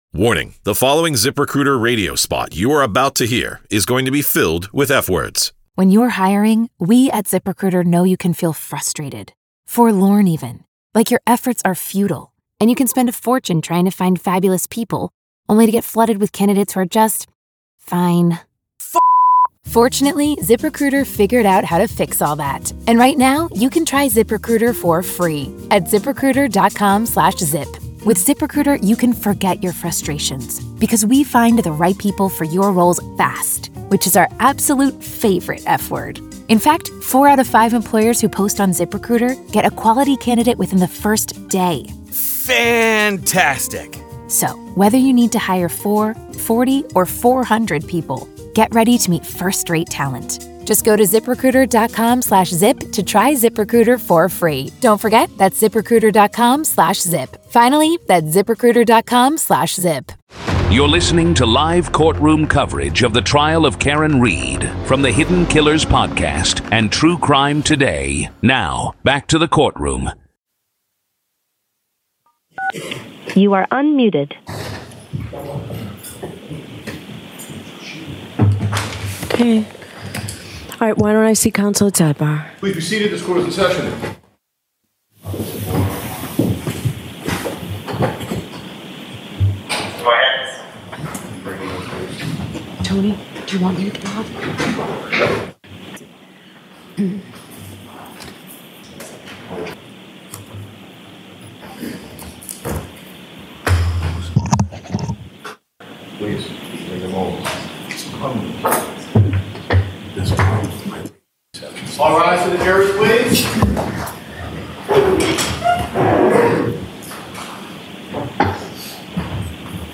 This is audio from the courtroom